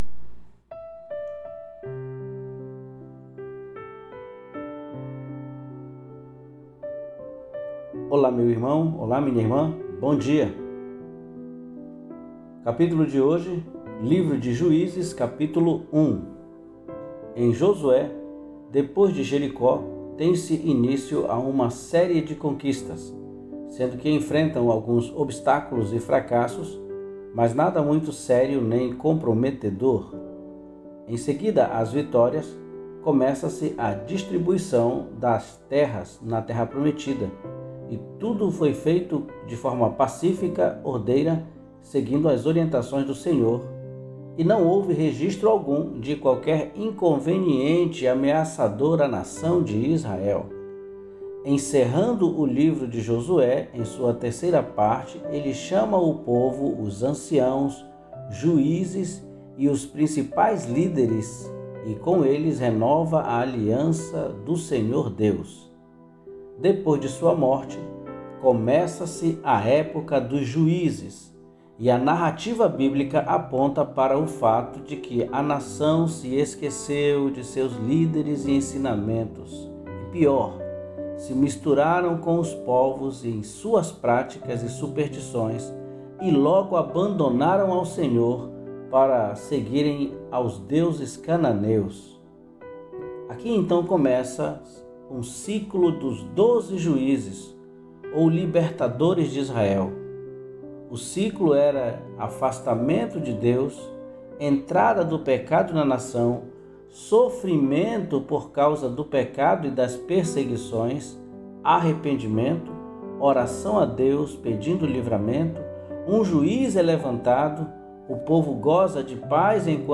PODCAST DE MEDITAÇÃO BÍBLICA DE HOJE